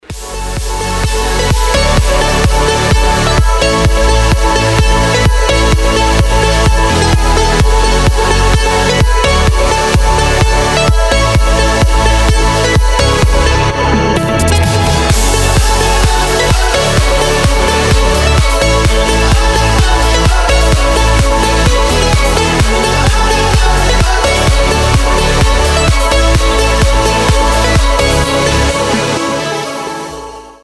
• Качество: 256, Stereo
dance
club
progressive house